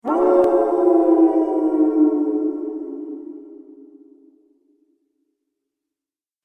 دانلود صدای گرگ 4 از ساعد نیوز با لینک مستقیم و کیفیت بالا
جلوه های صوتی